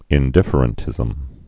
(ĭn-dĭfər-ən-tĭzəm, -dĭfrən-)